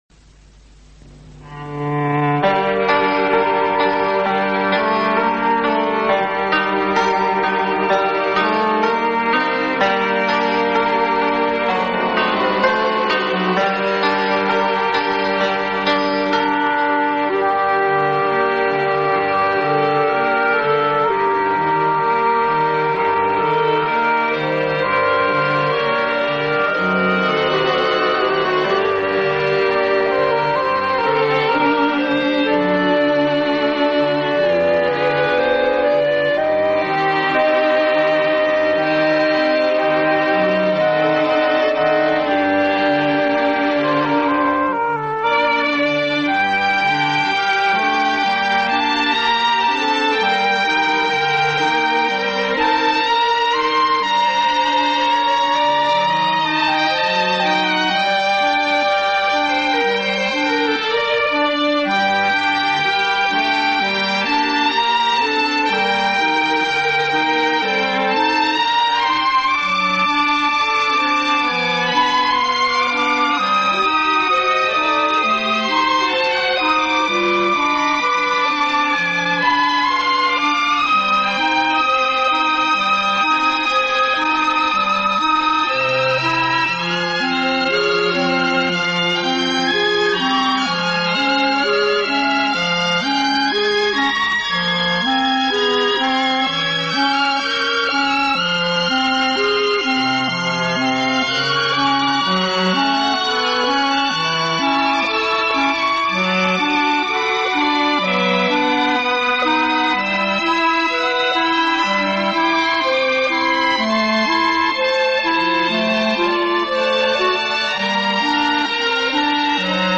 دانلود دکلمه رباعیات ابوسعید ابوالخیر با صدای بیژن مفید با متن دکلمه